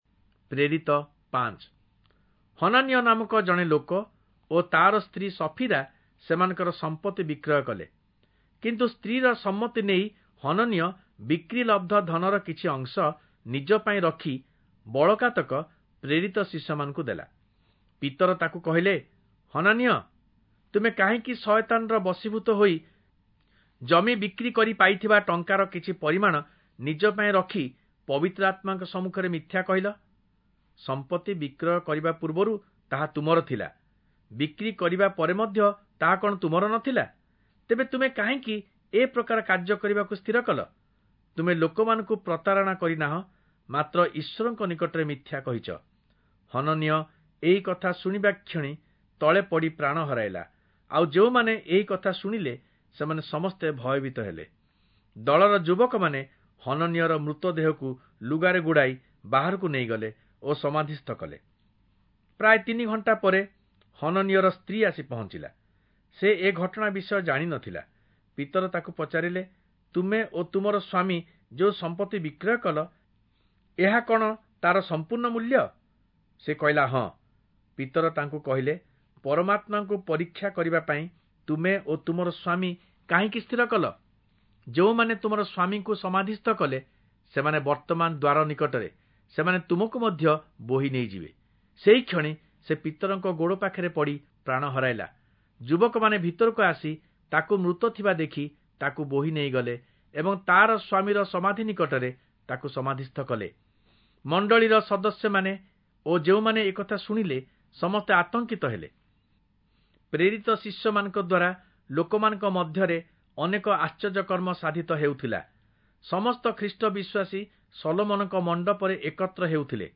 Oriya Audio Bible - Acts 9 in Guv bible version